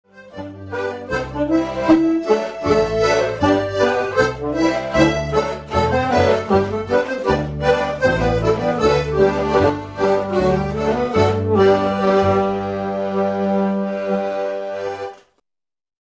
English-style Pub Band and a rollicking band
High energy music for dancing ~ listening ~ drinking rants, hornpipes, jigs, schottisches,
tenor banjo and melodeon
bass and concertina
whistle, flute, pipe and tabor, and saxophone.